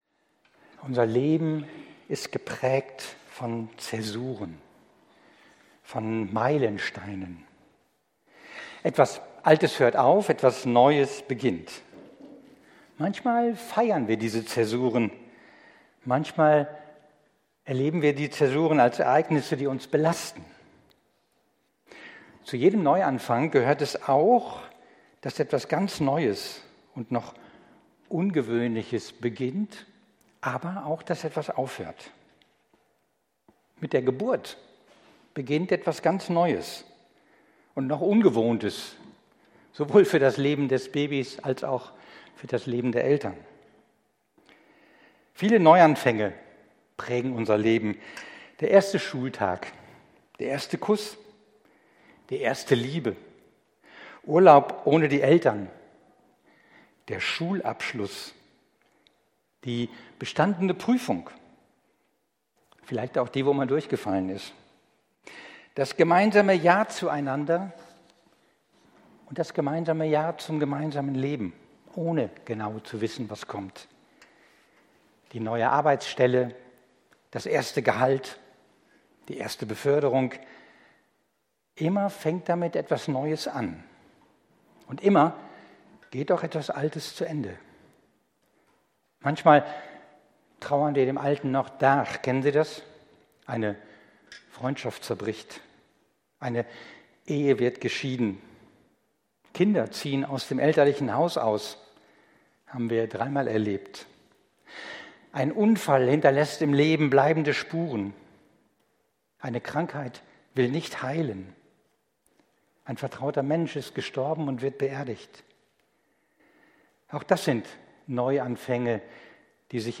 Predigt am Sonntag